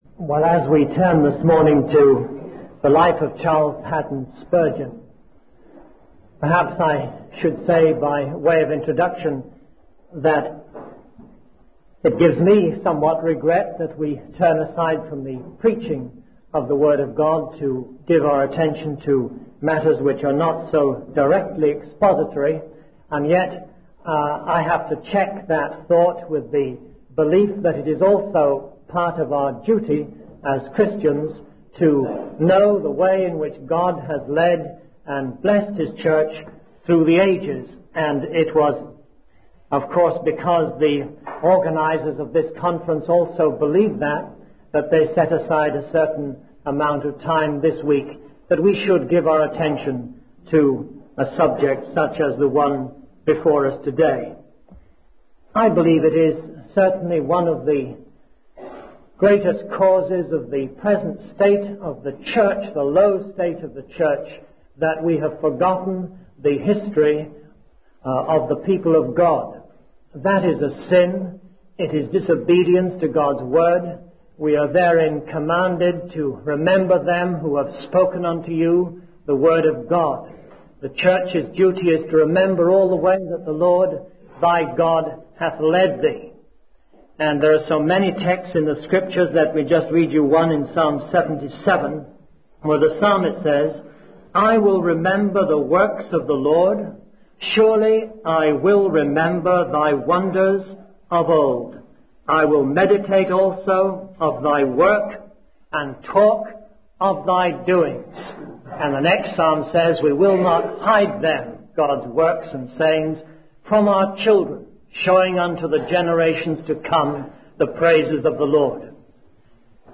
In this sermon, the preacher, Spurgeon, addresses the sins of society, particularly the oppression of the poor and the toleration of immorality. He emphasizes that this world is not the place of punishment for sin, but rather a place where sin is prevalent.